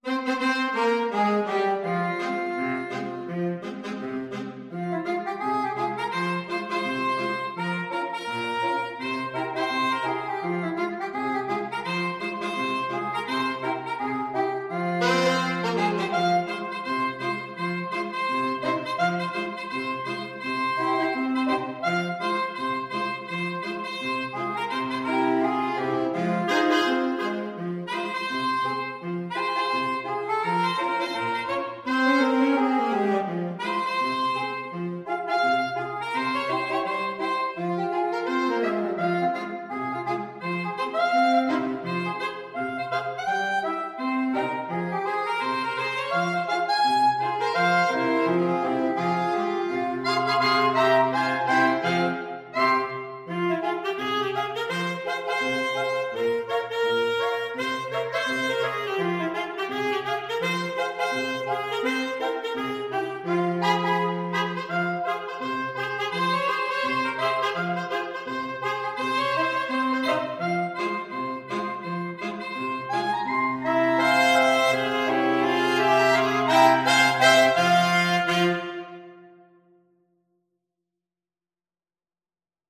is a well-known African-American spiritual.
Quick Swing = 84
2/2 (View more 2/2 Music)